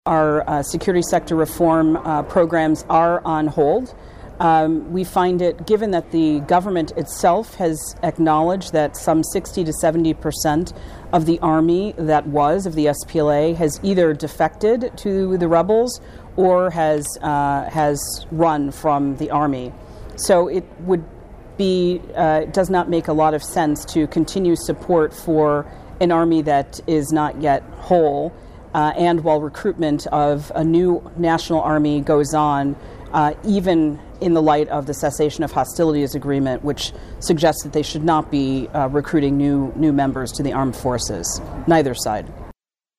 "This is not a conflict that should or can be won through the use of force and weapons," Page told VOA in an interview conducted Thursday at the State Department in Washington.